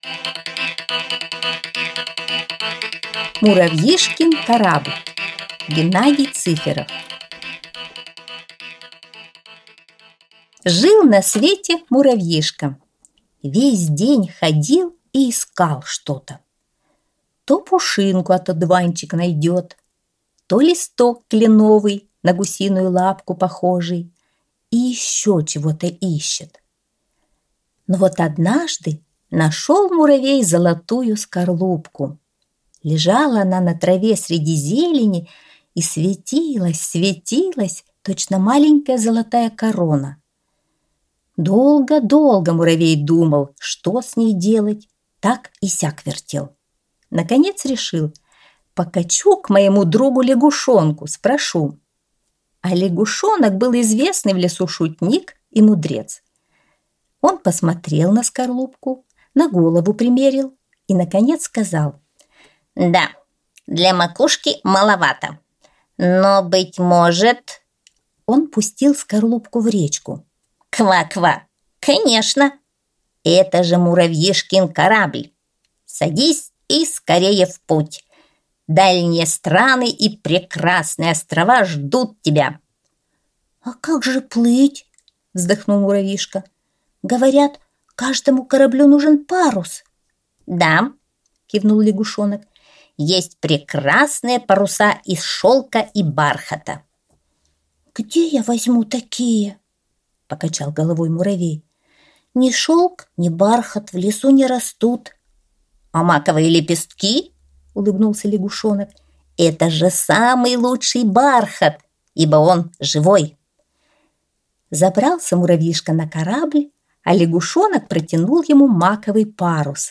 Аудиосказка «Муравьишкин корабль»